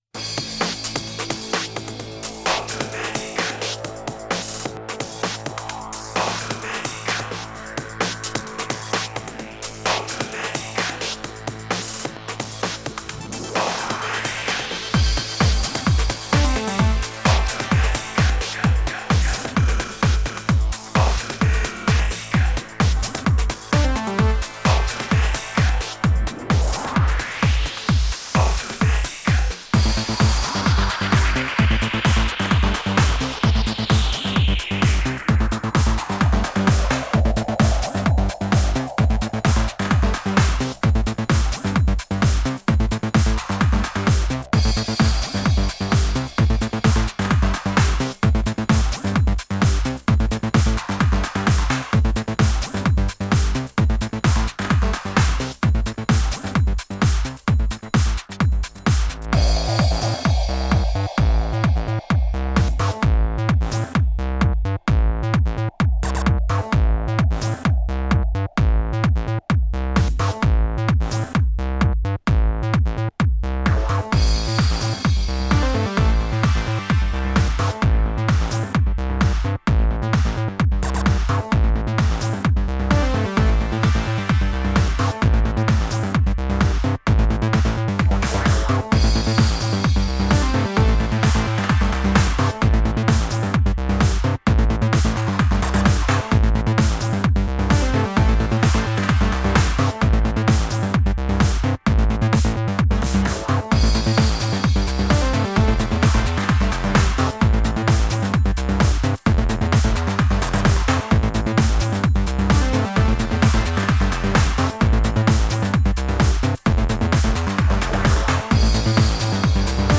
Club